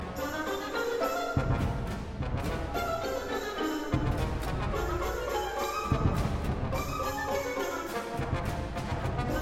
oboes, xylophone, mandolin, piano, harp, violins and violas
trombones, ‘cellos and basses